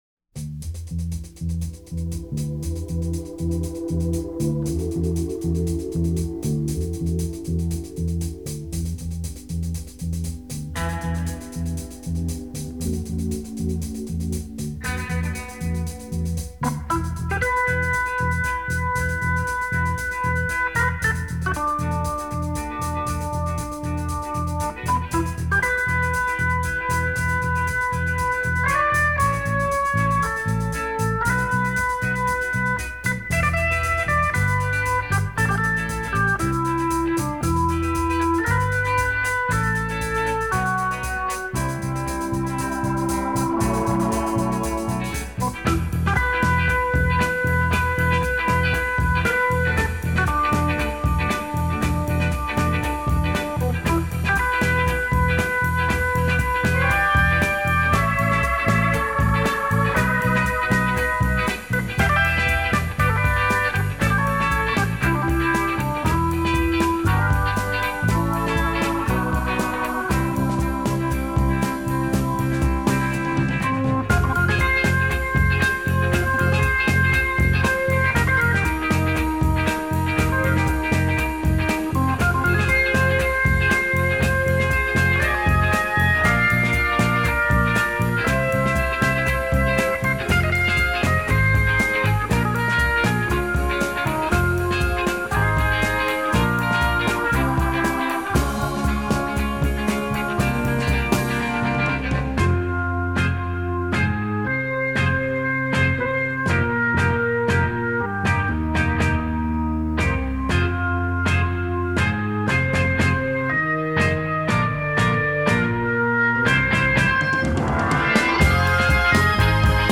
키보드 연주자가 그룹리더로 음악에서도 키보드 연주가 듣기 좋다.
역동적이고 영혼이 깃든 듯한 사운드가 특징이다.